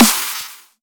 Snare_03.wav